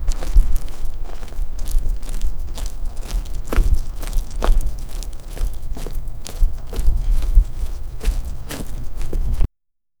very small animal walking on the grass
very-small-animal-walking-dj4mrsob.wav